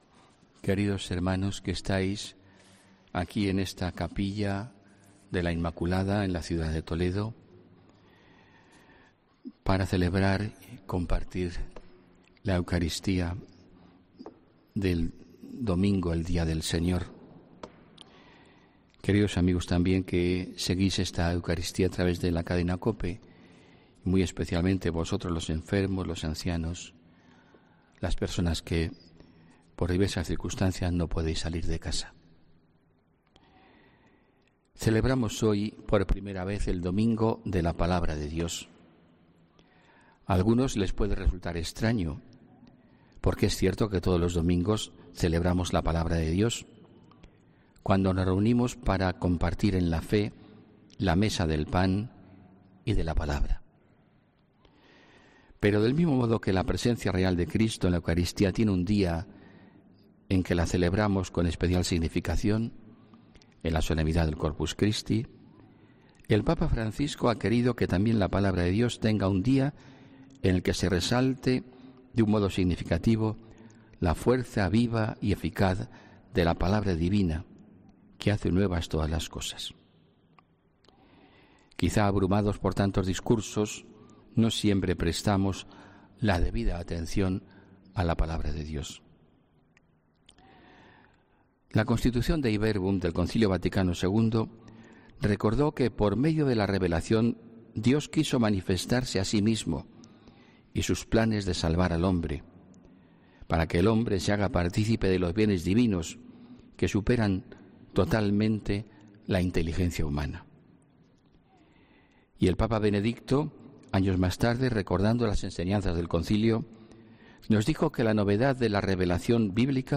HOMILÍA 26 ENERO 2020